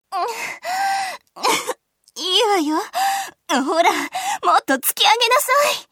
サンプルボイスは各キャラクターの下にあります